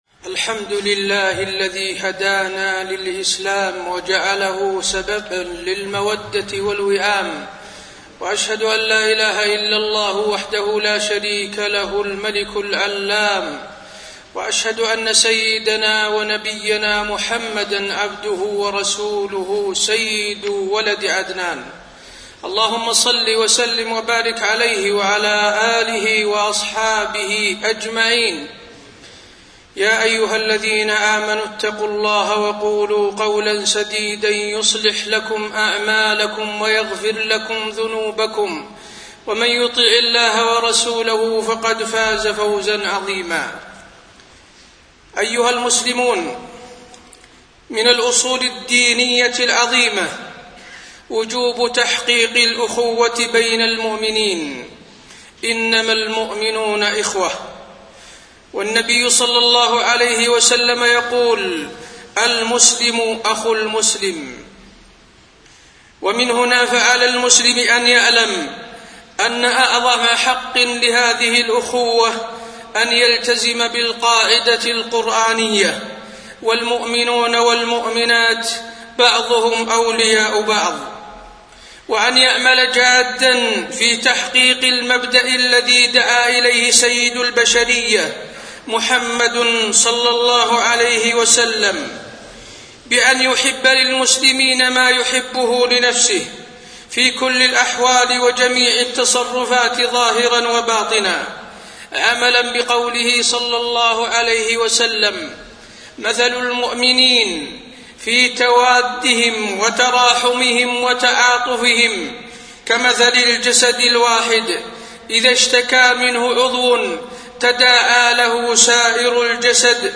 تاريخ النشر ٨ رجب ١٤٣٢ هـ المكان: المسجد النبوي الشيخ: فضيلة الشيخ د. حسين بن عبدالعزيز آل الشيخ فضيلة الشيخ د. حسين بن عبدالعزيز آل الشيخ الإخوة الإيمانية